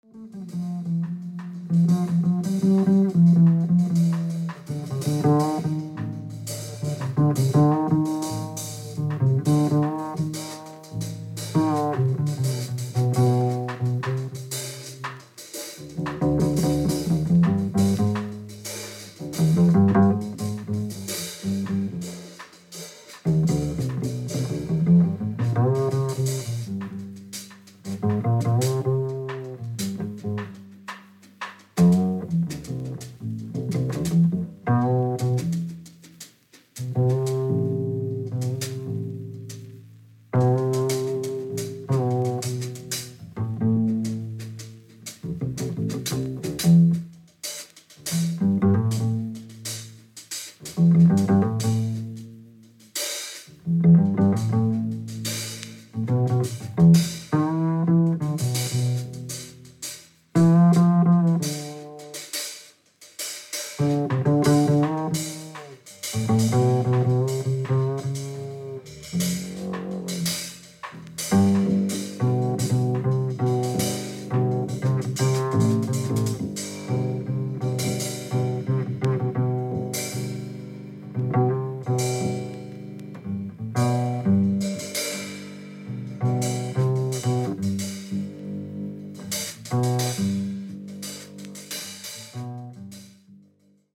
66年・オランダ・Haarlemでのライブ録音盤